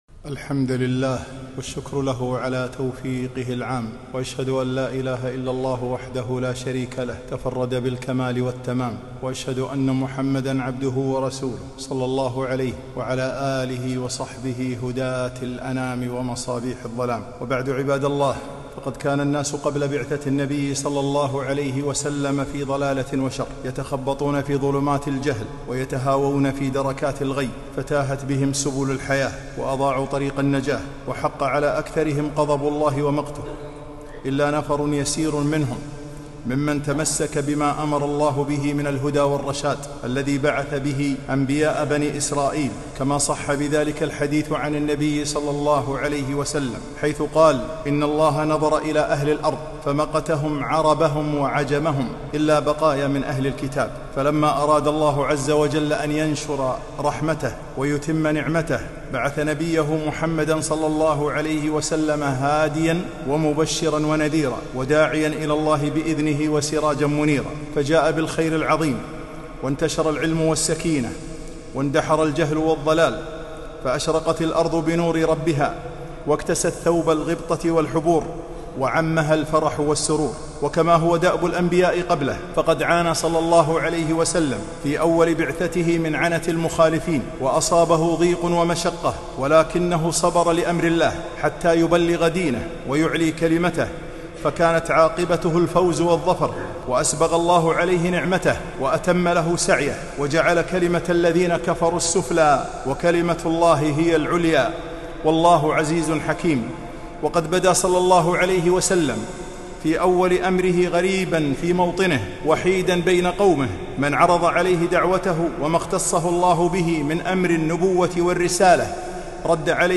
خطبة - غربة الإسلام